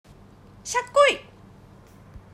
そこで、読者の皆様によりリアルに「はこだて弁」を体感していただきたく、全ての例文の音声収録をしました！